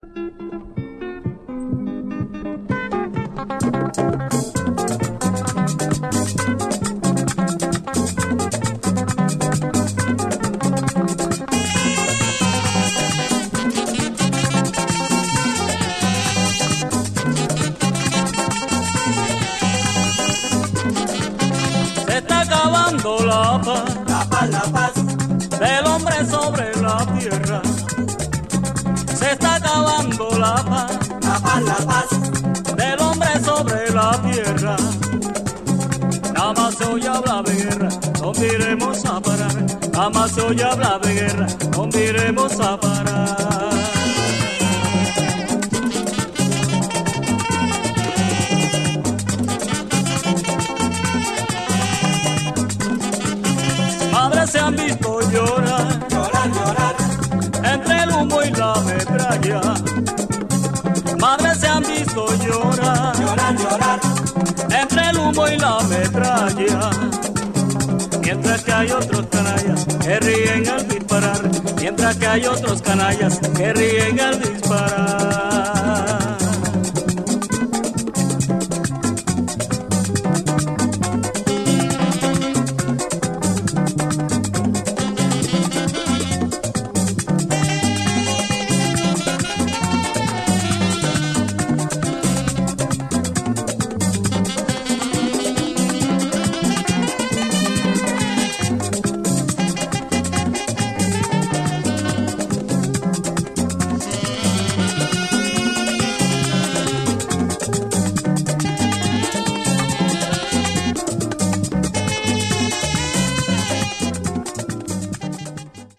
Heavily afro influenced cumbias.